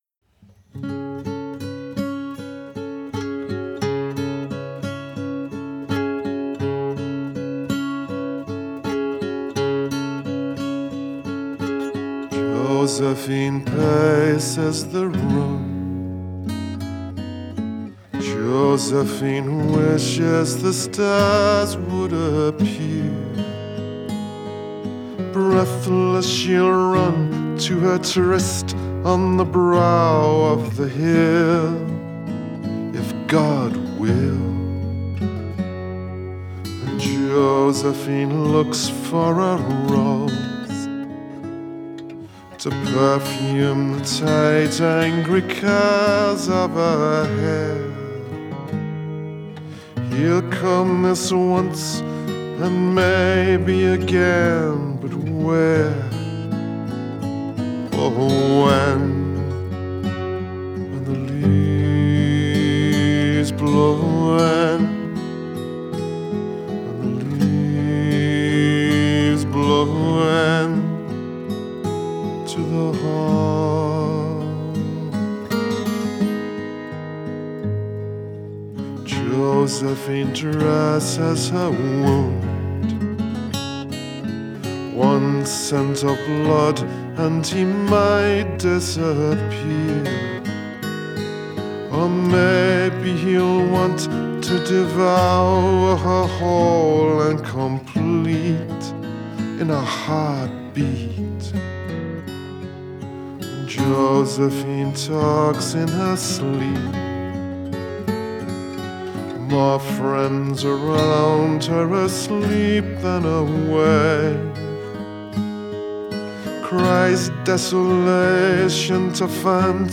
Genre: Folk, Singer-Songwriter